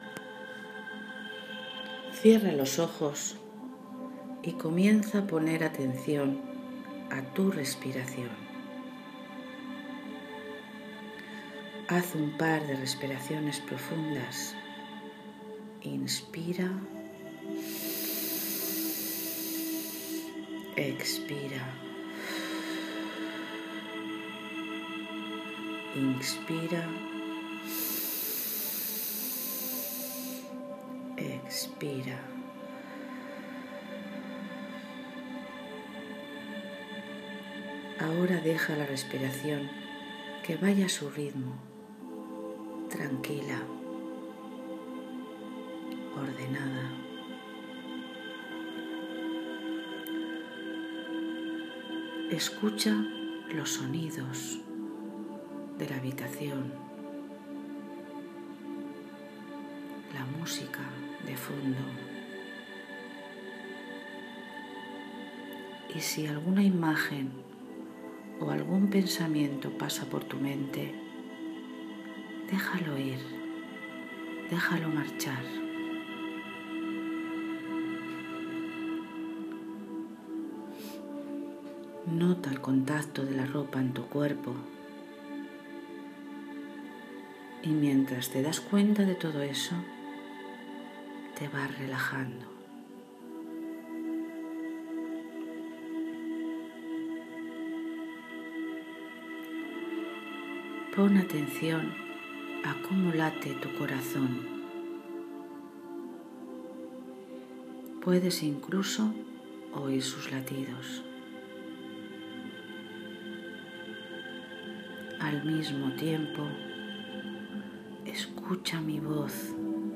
Visualizacio_n-Rio-Termal.m4a